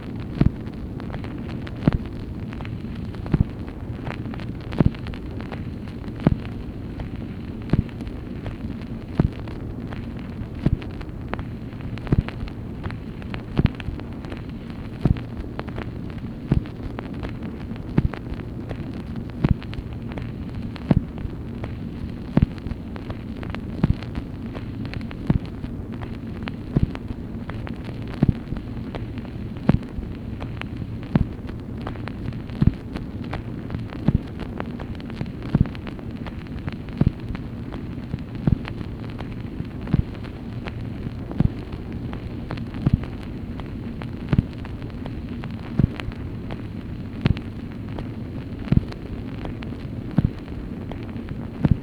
MACHINE NOISE, March 5, 1964
Secret White House Tapes | Lyndon B. Johnson Presidency